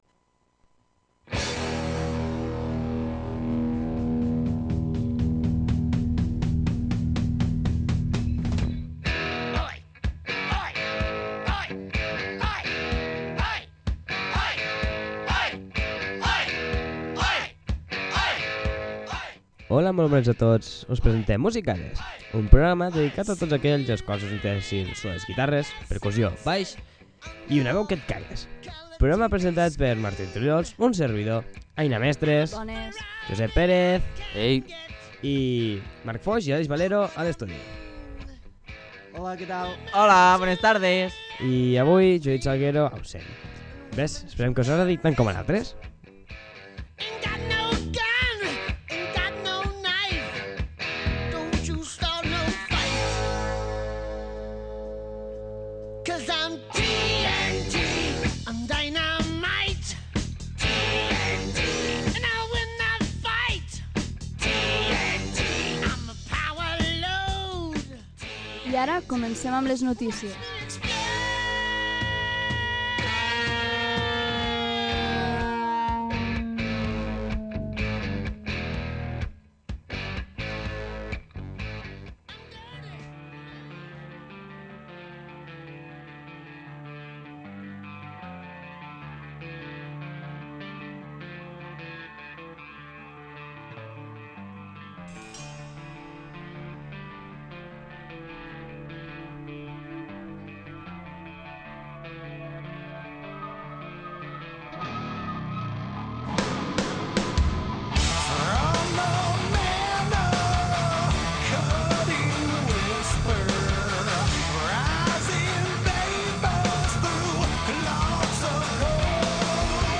Primer programa de la nova temporada. Continuem amb la mateixa essència, apostant pel Rock i amb l’energia de les veus més joves de l’emissora.